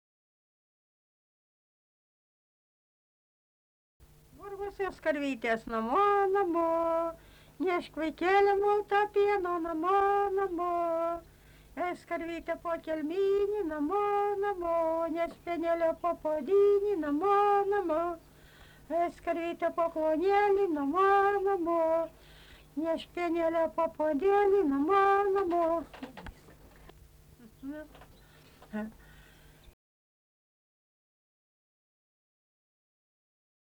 smulkieji žanrai
Obeliai
vokalinis